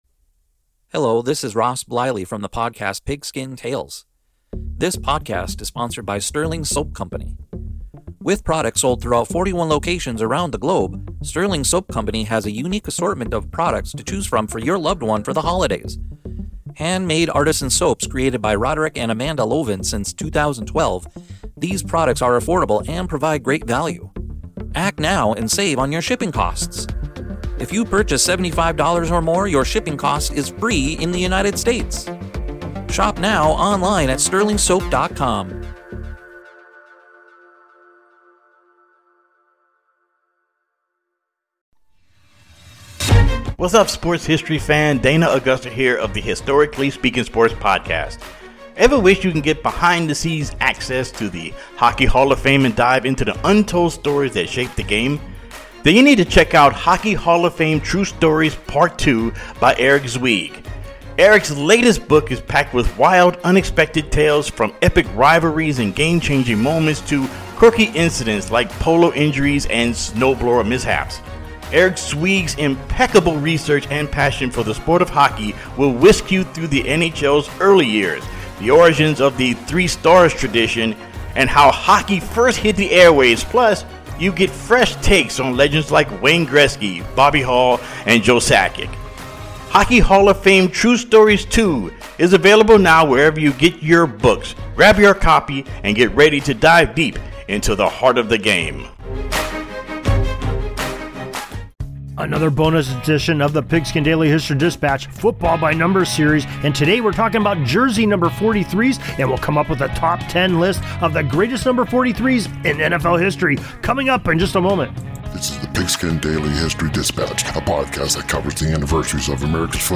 We also feature great music